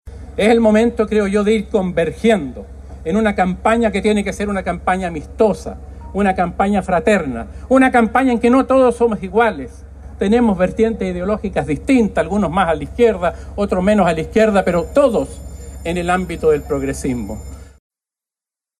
La inscripción se concretó el pasado miércoles, en el Servicio Electoral (Servel), donde se realizó un acto cuyo objetivo principal fue dar una señal de unidad en el sector.